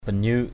/ba-ɲɯ:ʔ/ (cv.) binyâk b{vK 1.